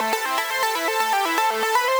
SaS_Arp05_120-A.wav